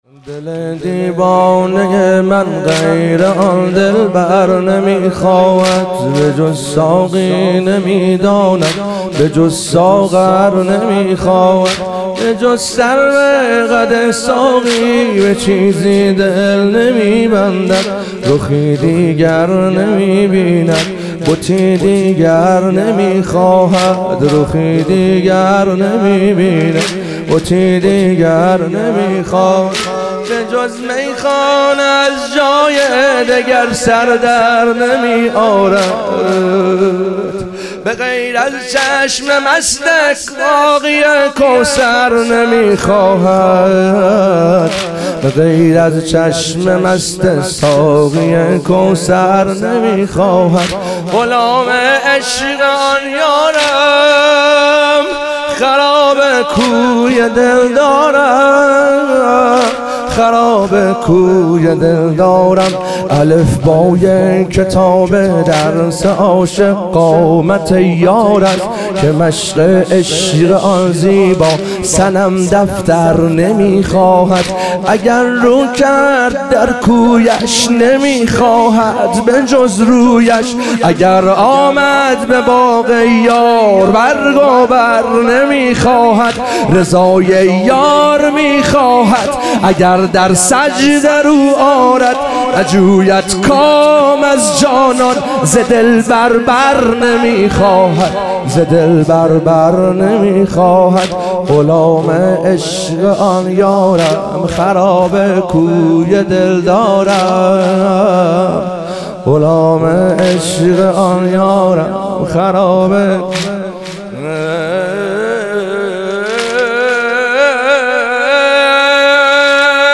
ظهور وجود مقدس حضرت زینب علیها سلام - واحد